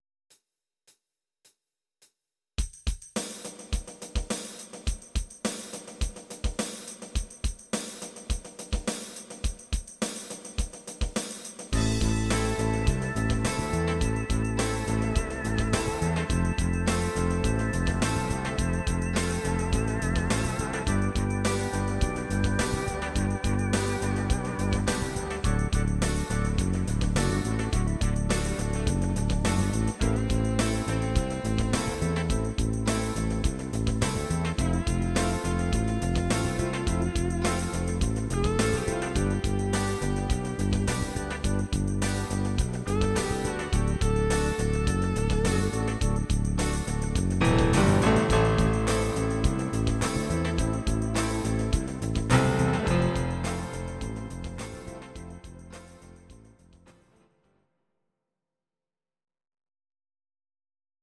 These are MP3 versions of our MIDI file catalogue.
Piano